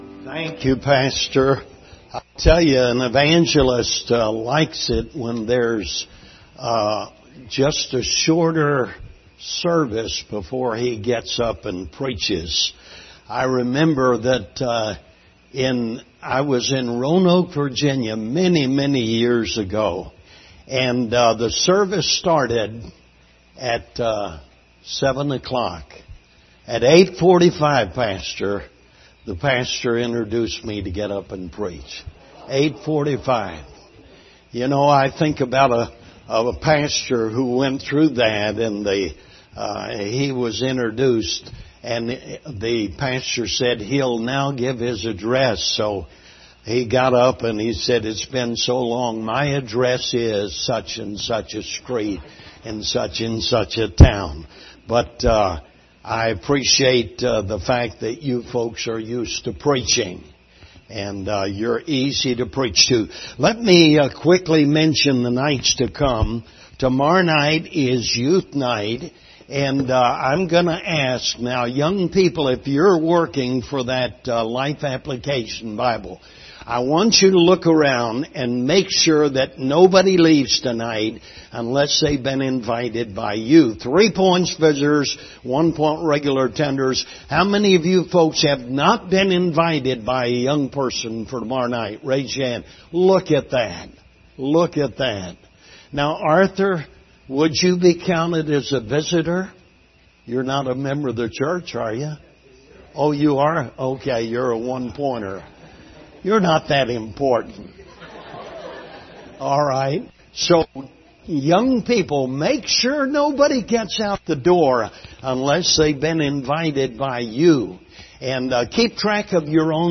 Revelation 6:1-2 Service Type: Revival Service Topics: prophecy , rapture « What Will You Do With Your Life?